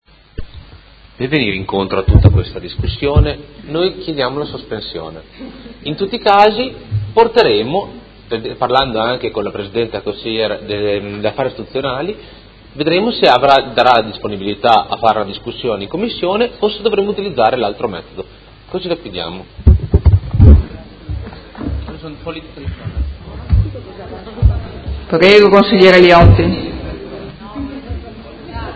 Seduta dell'11/01/2018 Ordine del Giorno presentato dal Movimento cinque Stelle avente per oggetto: Maggior coinvolgimento del Consiglio Comunale nel percorso delle nomine